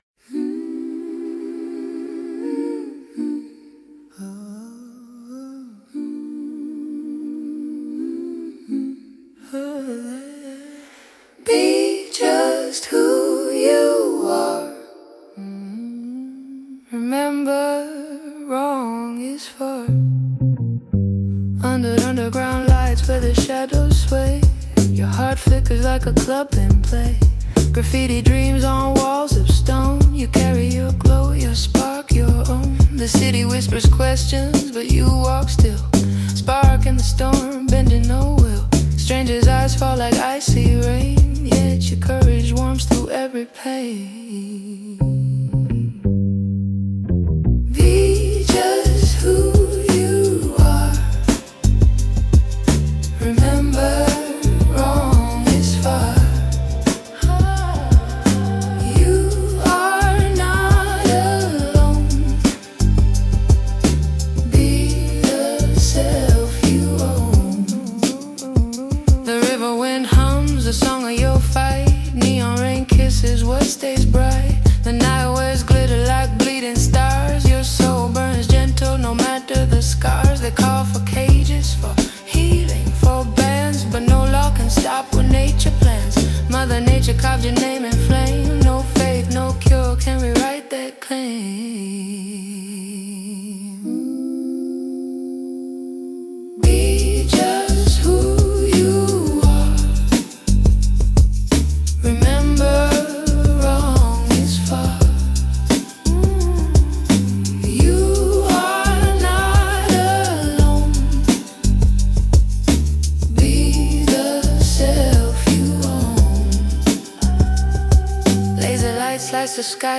This song is a celebration of diversity, a neon anthem for everyone, 🌈 whether man, woman, gay, lesbian, trans, non-binary, or queer. The repeated chorus shouts the core message loud and clear: 🌈 “Be just who you are!”